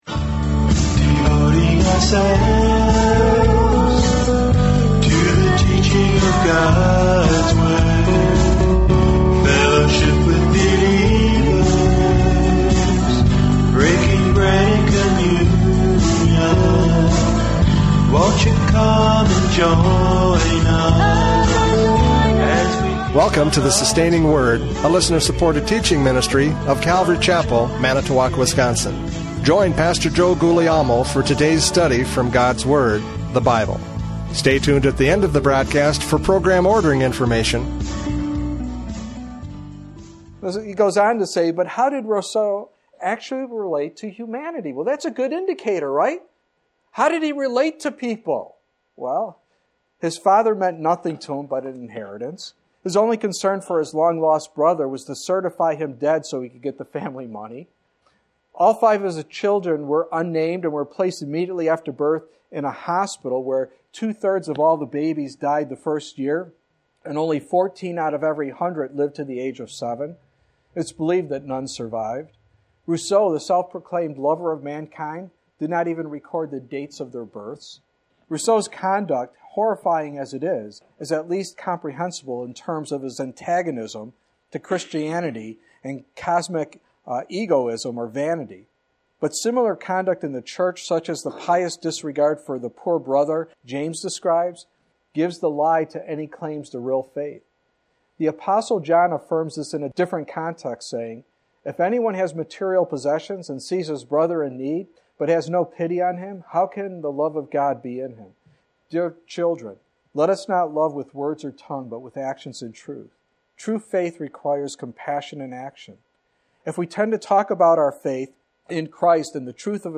James 2:14-20 Service Type: Radio Programs « James 2:14-20 Faith is Proved by Works!